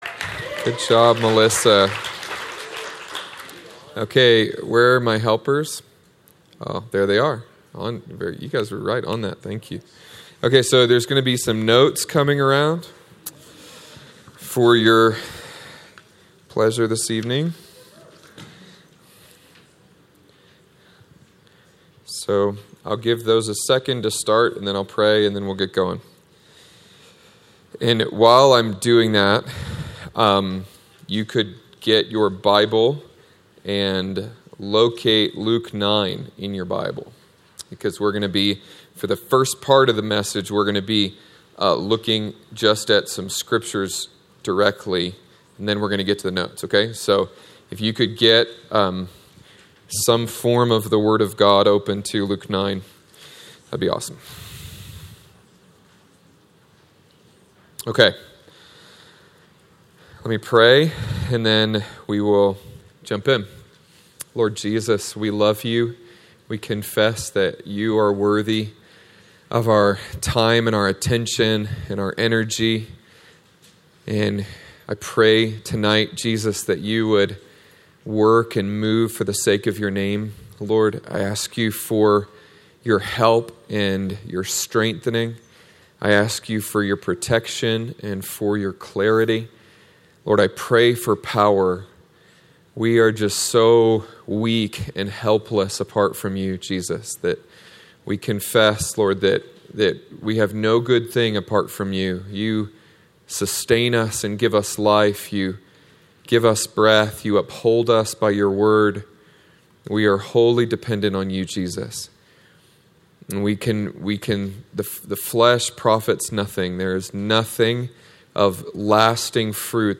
Spoken on October 10th, 2014 at the weekly Prayer Corps Gathering in Colorado Springs.